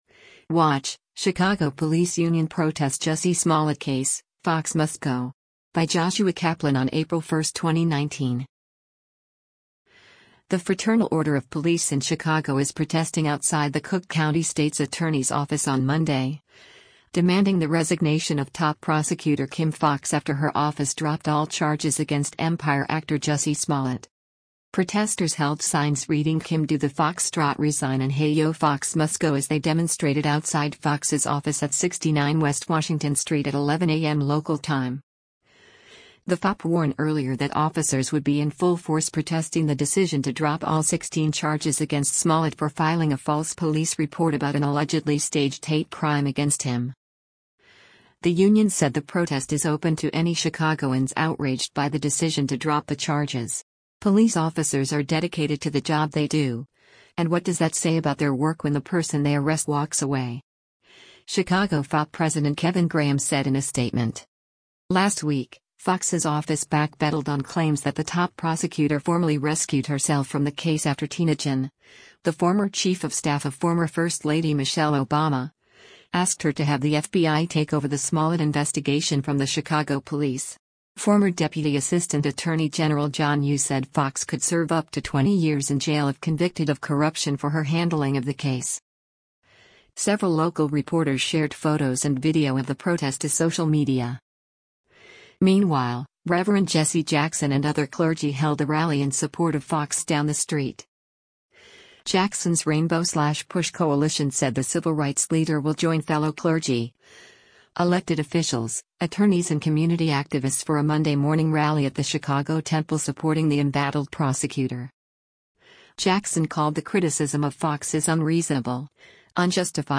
The Fraternal Order of Police in Chicago is protesting outside the Cook County State’s Attorney’s Office on Monday, demanding the resignation of top prosecutor Kim Foxx after her office dropped all charges against Empire actor Jussie Smollett.